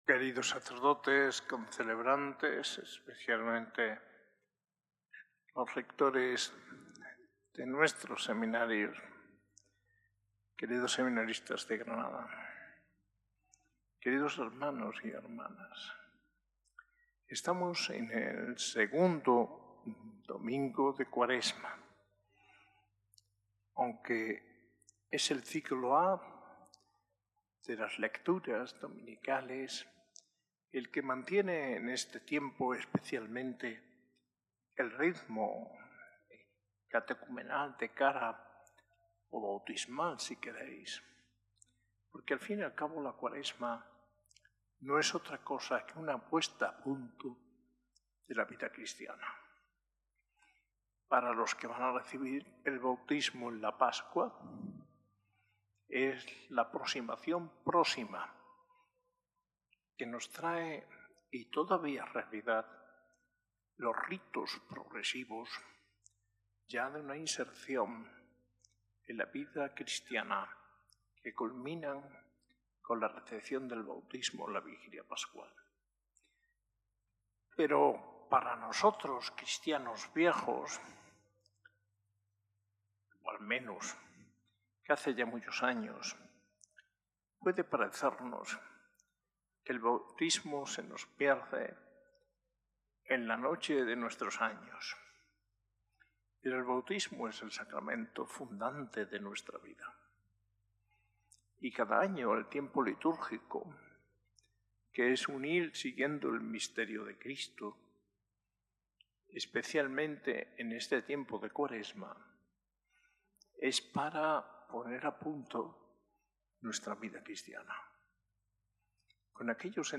Homilía del arzobispo de Granada, Mons. José María Gil Tamayo, en el II Domingo de Cuaresma, en la S.A.I Catedral el 16 de marzo de 2025.